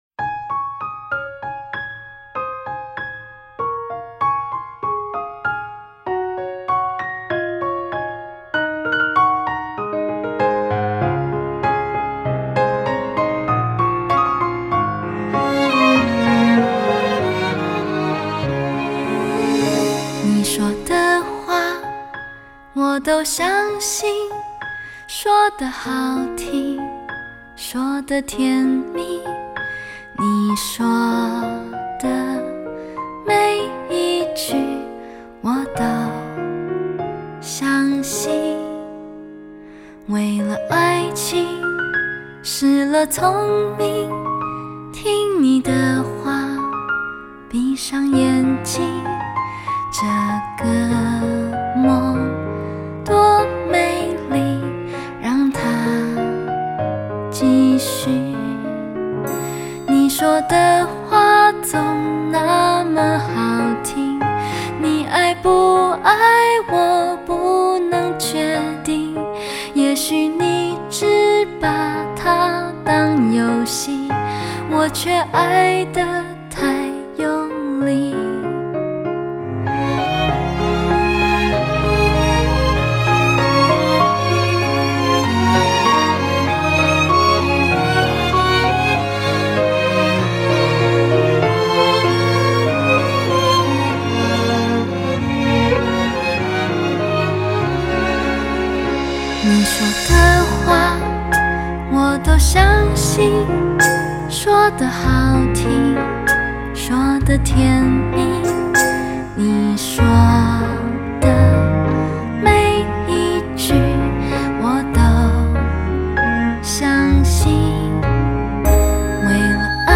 感慨着，一边听着这曲风平和甚至有点婉转悠扬的抒情，任那声线把我带入回忆。